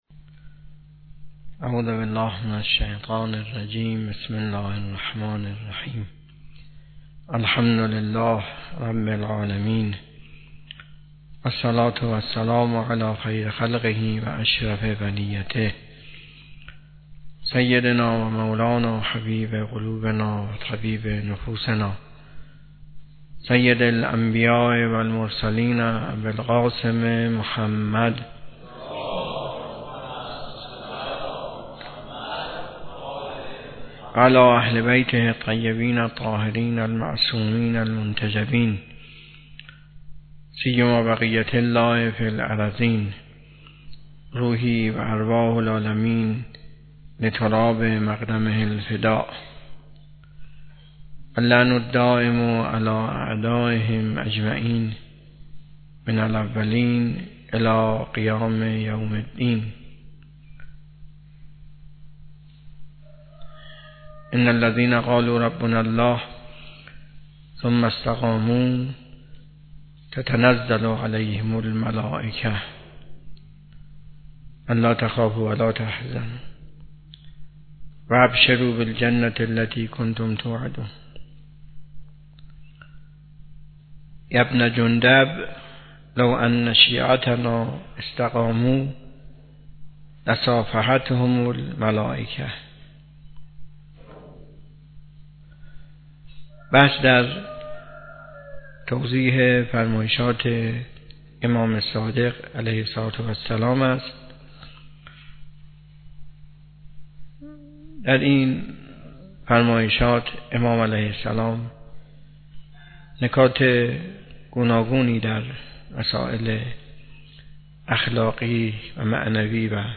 خیمه گاه - آیت الله محمد باقر تحریری - سخنرانی آیت الله تحریری 971007